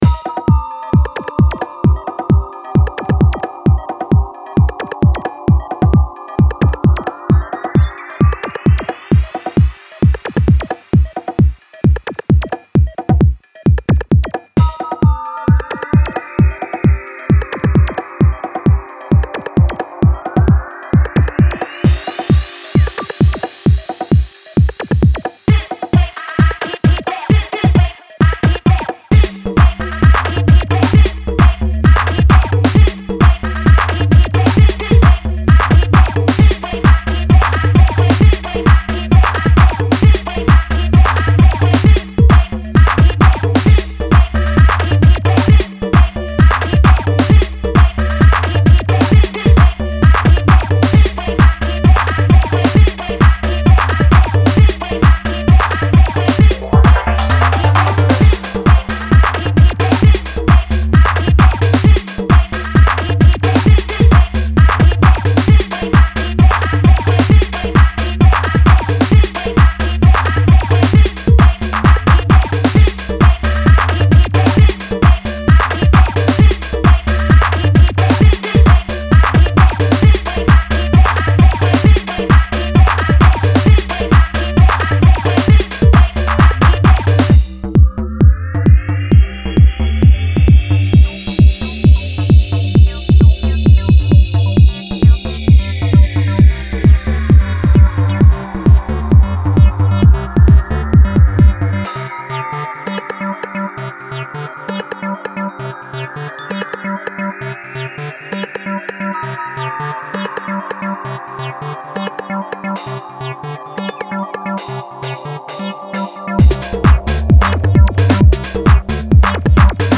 A true wtf acid beast
bangs traditionally and levels the place